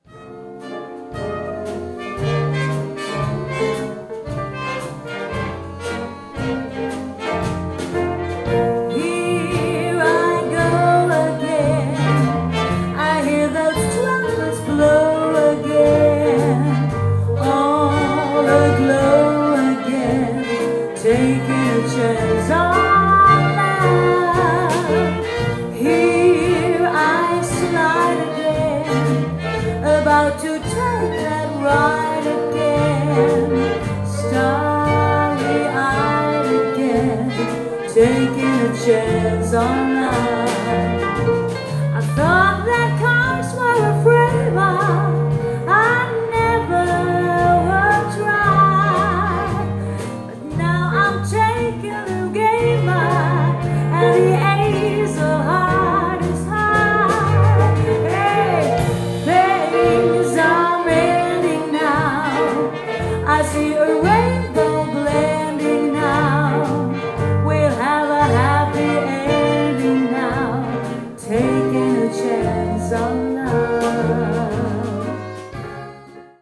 Big Band storband bröllop fest event party jazz